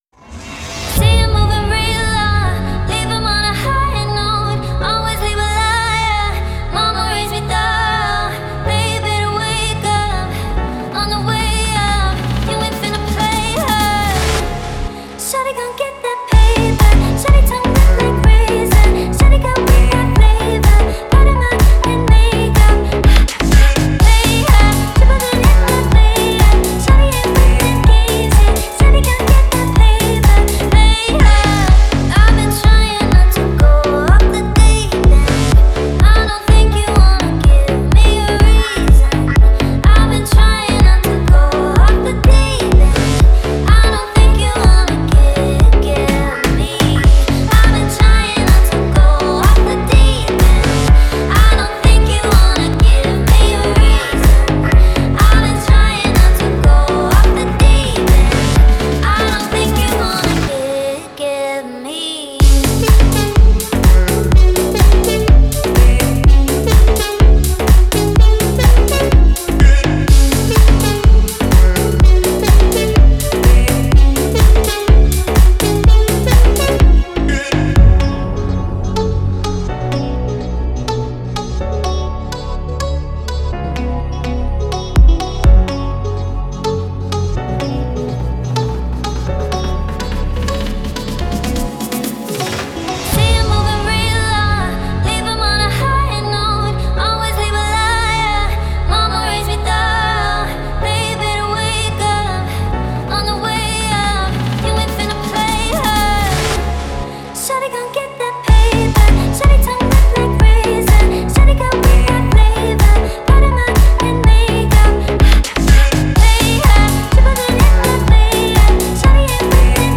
это захватывающая песня в жанре электронной музыки